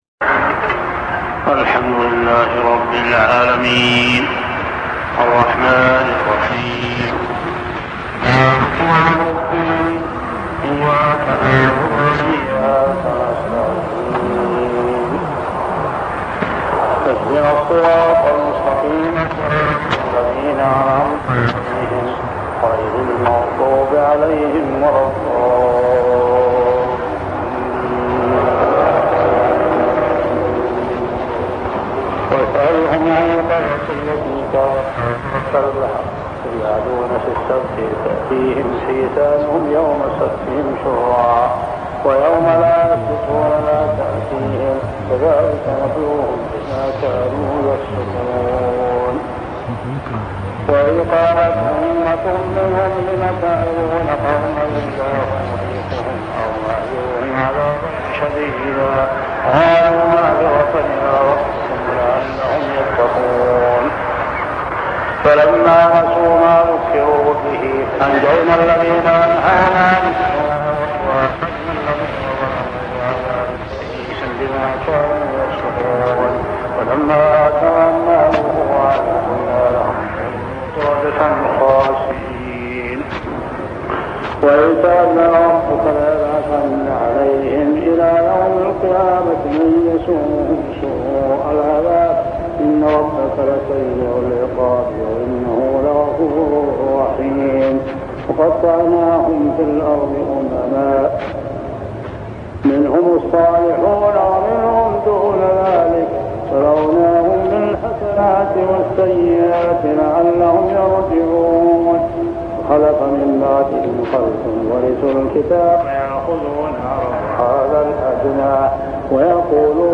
صلاة التراويح عام 1401هـ سورتي الأعراف 163-206 و الأنفال 1-40 | Tarawih prayer Surah Al-A'raf and Al-Anfal > تراويح الحرم المكي عام 1401 🕋 > التراويح - تلاوات الحرمين